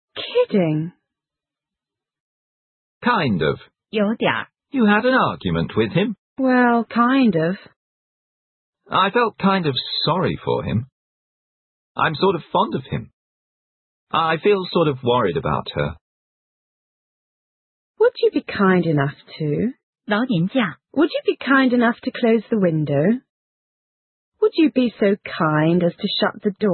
在快速语流中念作I}katndal, hsaaal，写成kinds,sortao